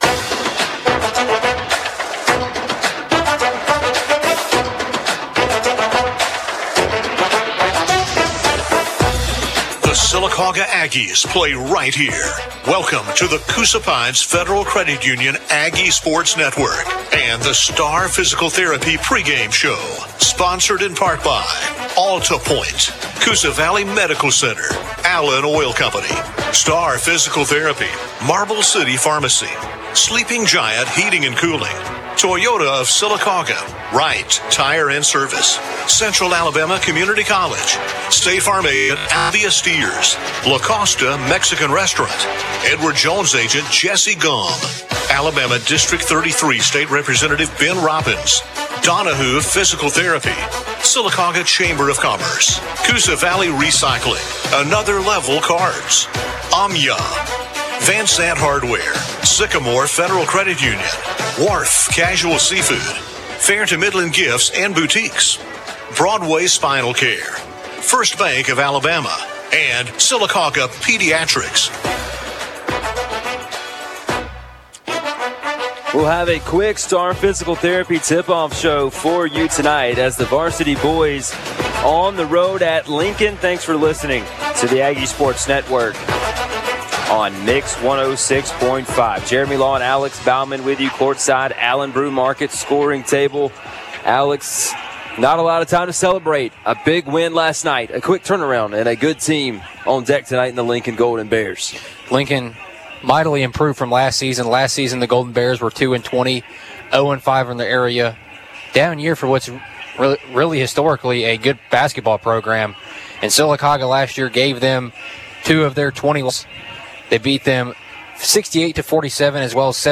game